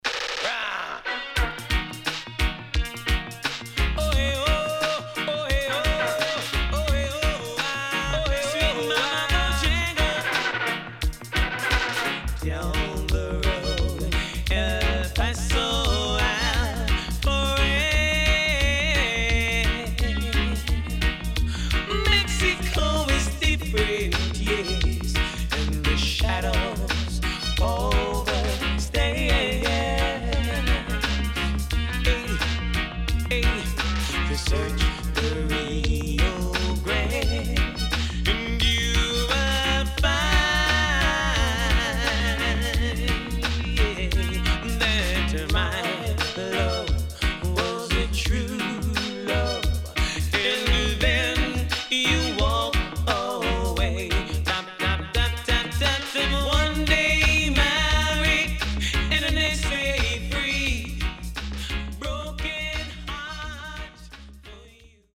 SIDE B:うすいこまかい傷ありますがノイズあまり目立ちません。